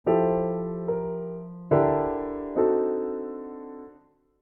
ダイアトニック・アプローチ
d-e-g-b♭というハーモナイズ
D7という観点で見ればg音が入っていたりするのはドミナントの機能を阻害しているわけですが、それは次の音に行く時に解消される前提でやっているわけなので、いわばsus4的な効果として機能します。